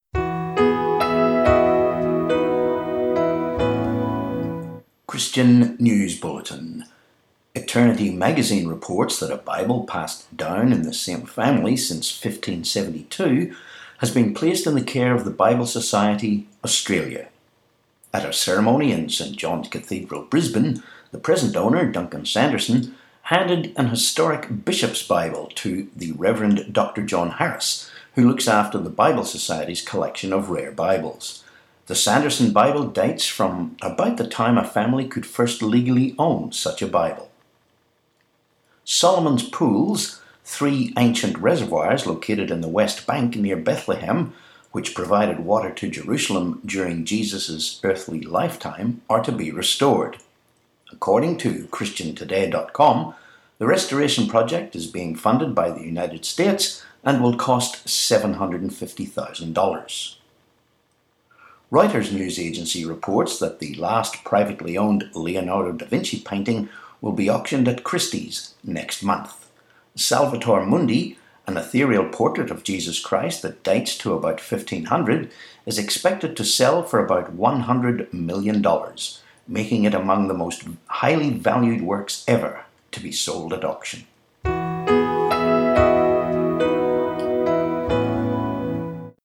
22Oct17 Christian News Bulletin